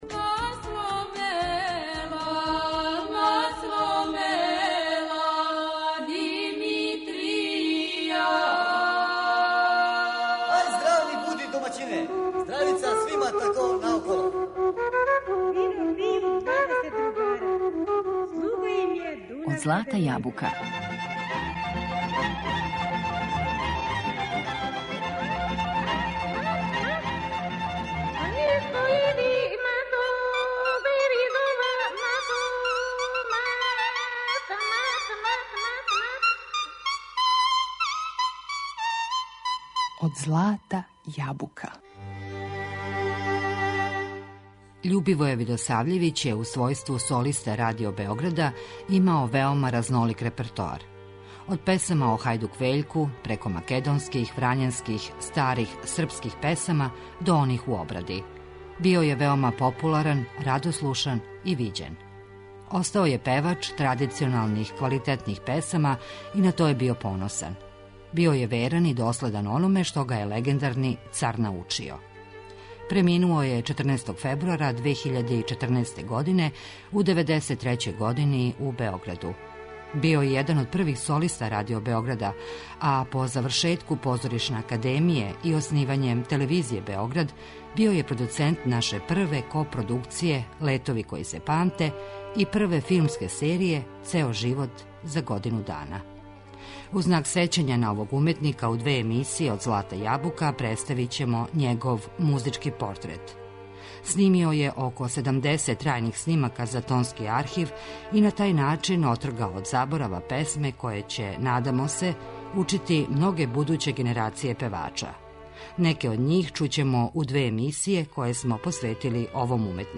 македонских, врањанских, старих српских песама